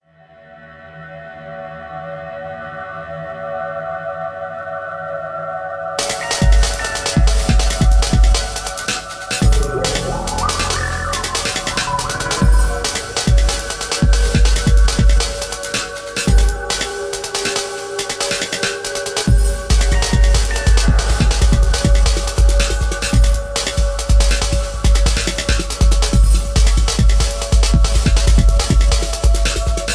Tags: dnb